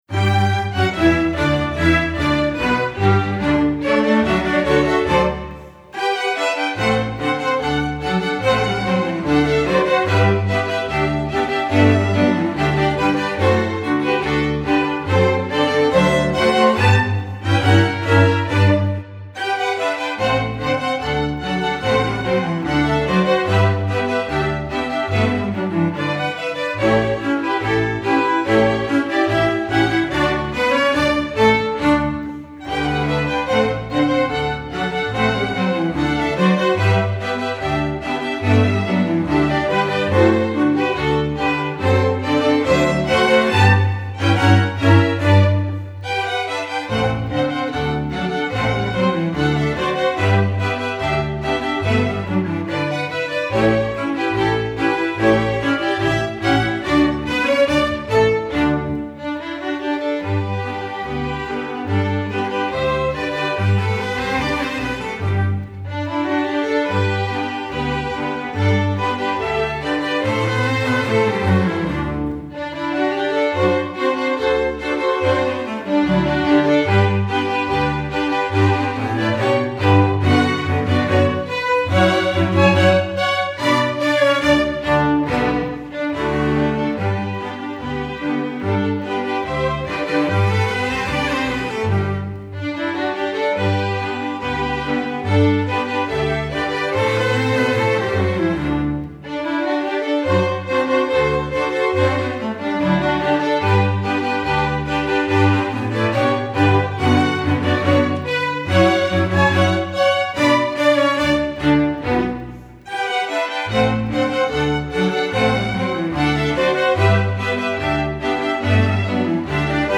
folk, traditional